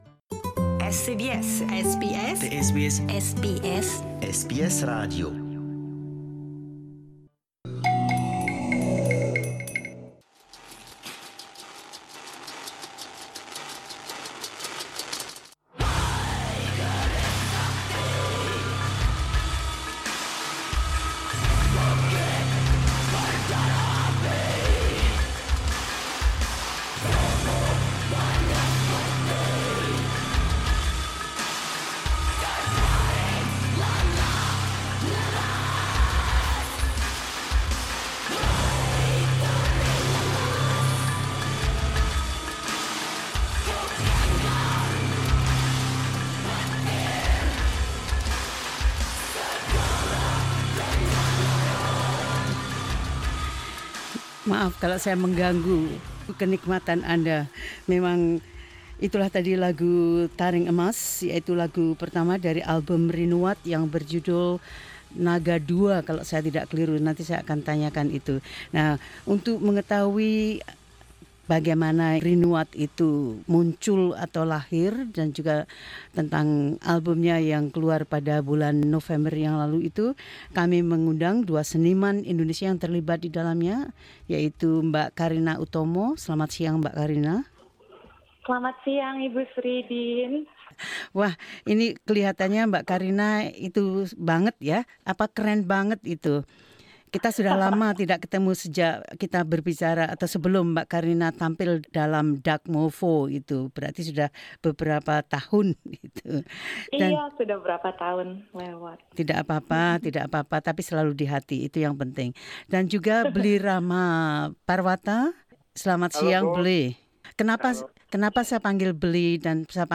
It harmonised the sounds of modern metal with the scales and rhythms of traditional Indonesian music and instruments. The result is powerful and emotional.